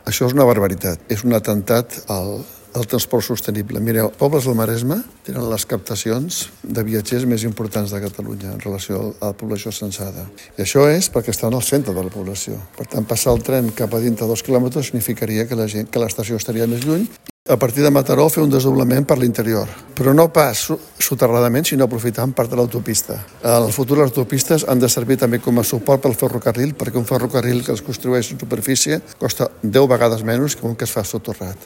Acte de campanya de Comuns Sumar a Calella, amb propostes per millorar el servei del tren a la comarca.